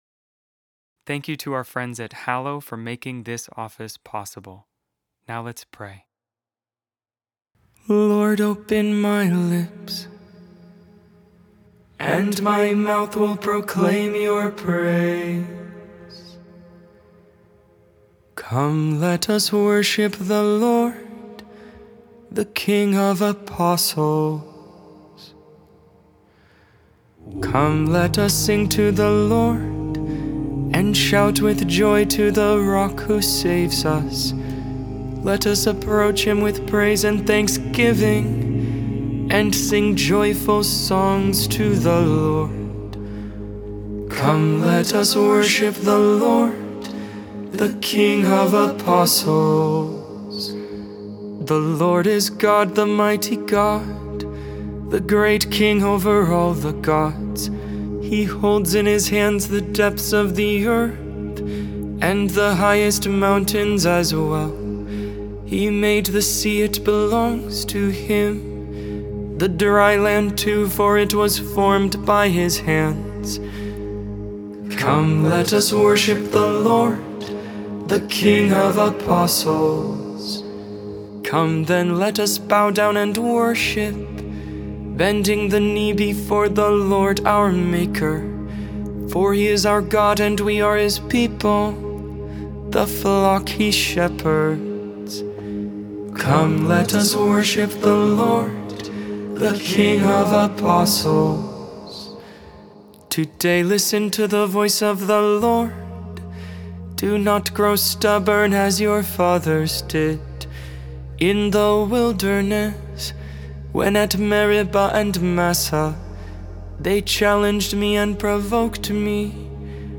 Made without AI. 100% human vocals, 100% real prayer.